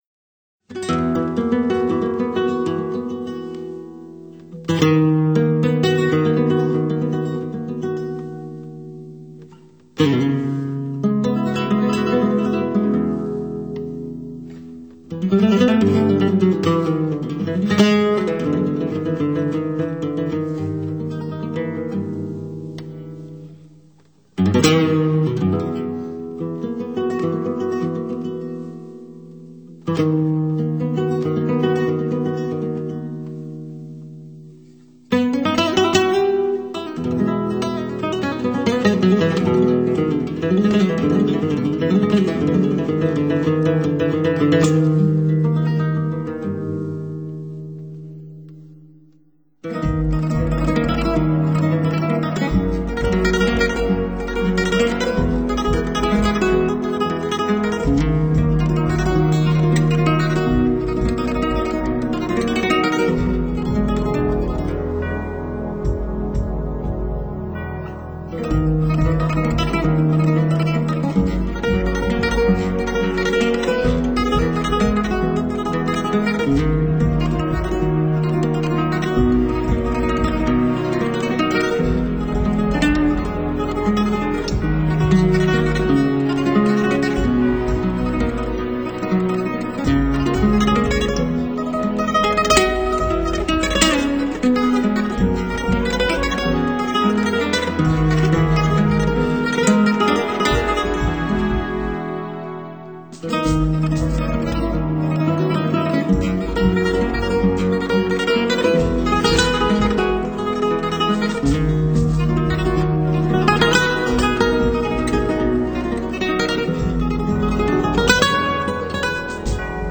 这张现代的吉普赛音乐在摩洛哥节奏式的伴奏下，巧妙的将大量地中海传统音乐与交错 的西班牙吉他调子结合在一起